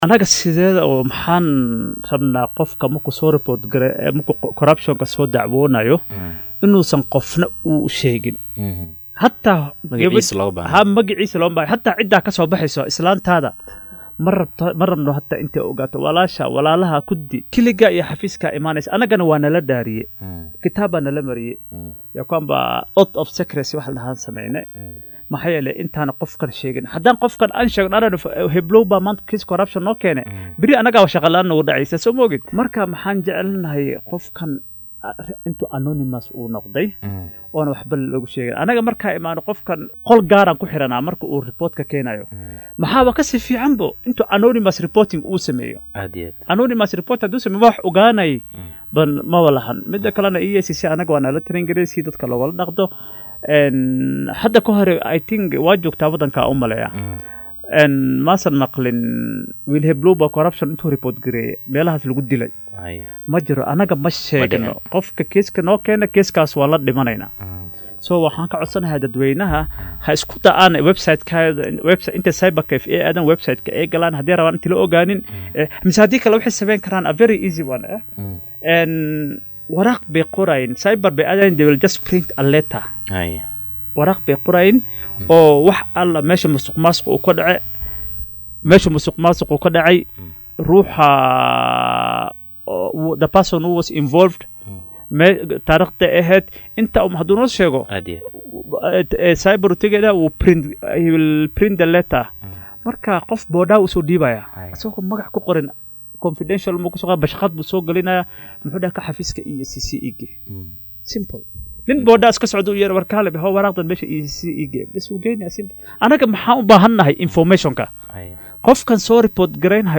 Sarkaal sare oo ka tirsan guddiga anshaxa iyo ladagaalnka Musuqmaasuqa ee EACC oo saaka marti ku ahaa barnaamijka Hoggaanka Star ayaa inooga warbixiyay waxyaabo badan oo ku saabsan la dagaalanka Musuqmaasuqa.